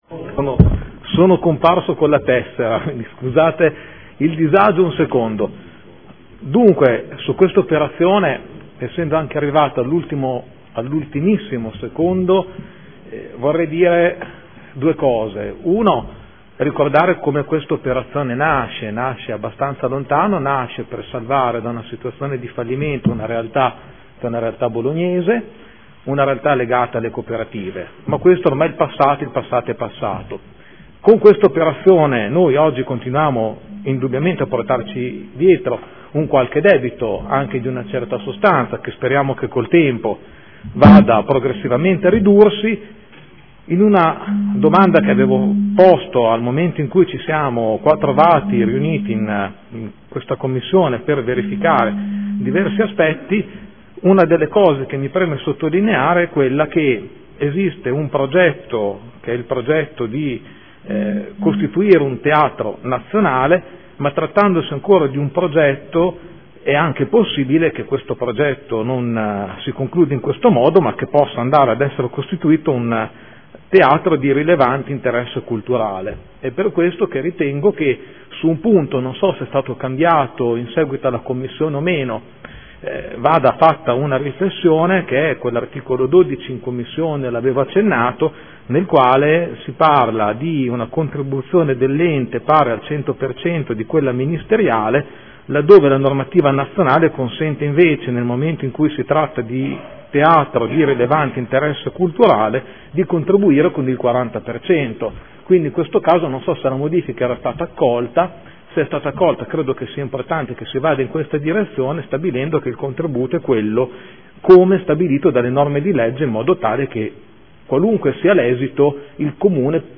Giuseppe Pellacani — Sito Audio Consiglio Comunale
Proposta di deliberazione: Fondazione Emilia Romagna Teatro – Approvazione proposte di modifica statutarie. Dibattito